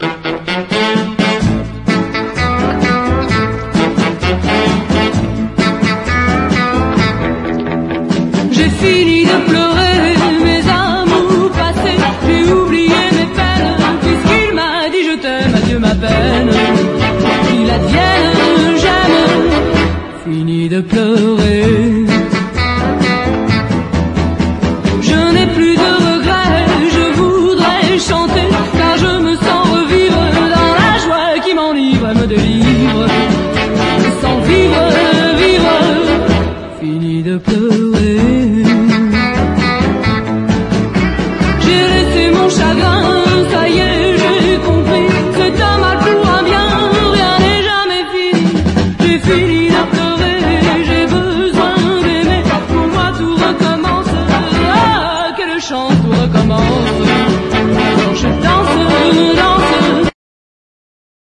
EASY LISTENING / VOCAL / CHORUS
楽しくて美しいコーラス人気作！
こちらは全編に美しいコーラスが入った作品。ラテン・ビートで疾走する
愉快にスウィングする